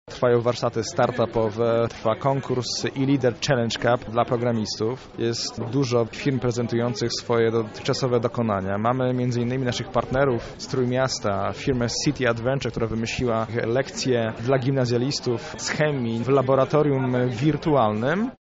O tym, co jeszcze czeka na uczestników, mówi